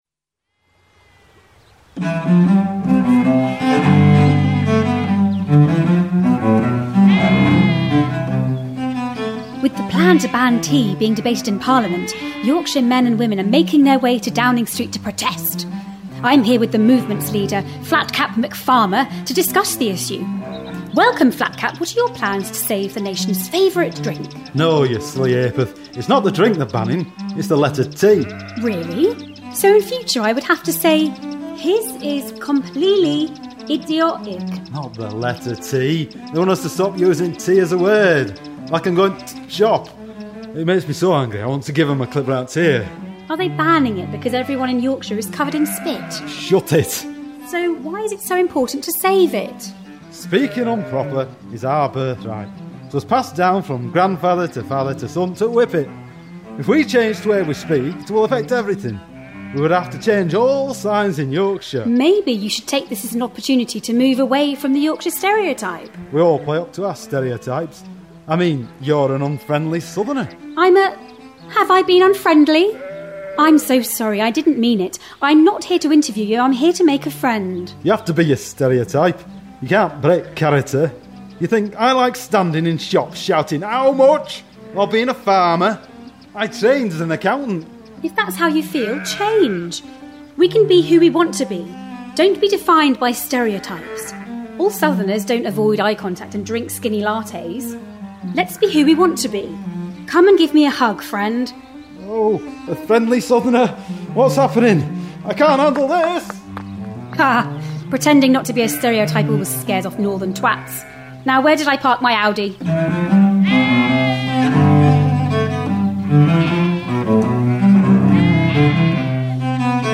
Our roving reporter has been sent to cover a protest in Yorkshire.